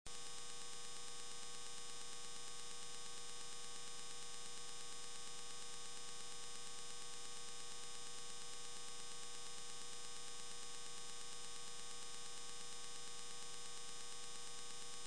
17-5kHz.wav (2,5 MB),
17-5kHz.mp3